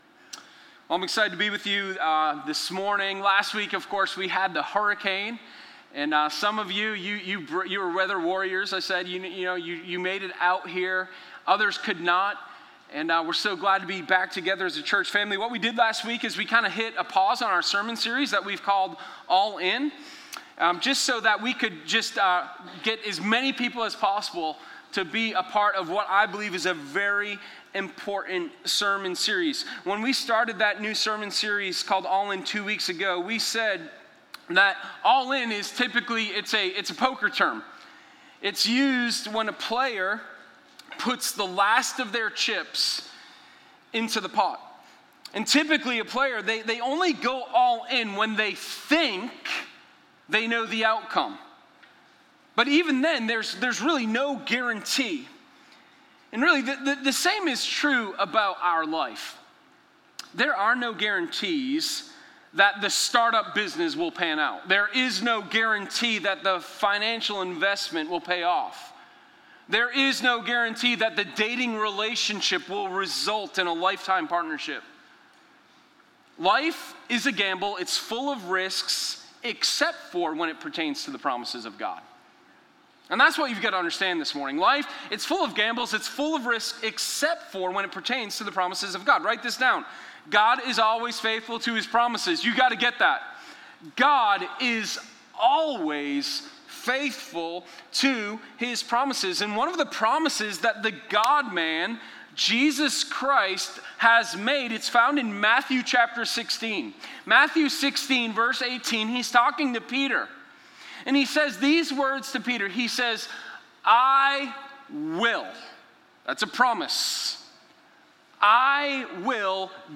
Sermon0923_Sunday.mp3